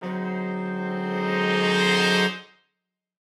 Index of /musicradar/gangster-sting-samples/Chord Hits/Horn Swells
GS_HornSwell-D7b2b5.wav